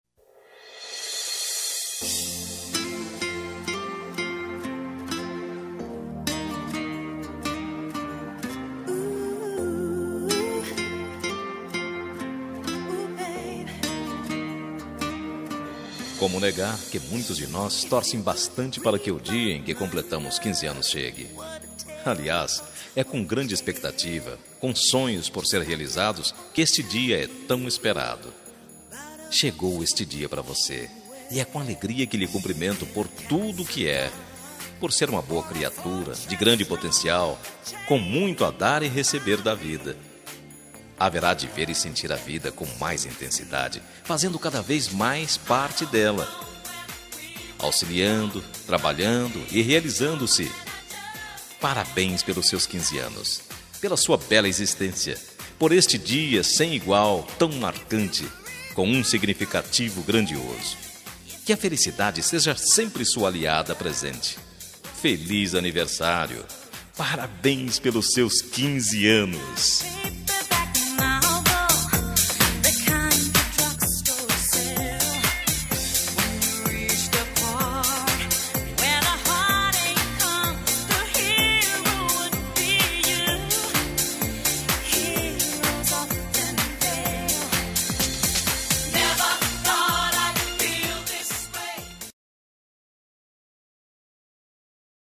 Aniversário de 15 Anos – Voz Masculina – Cód: 33383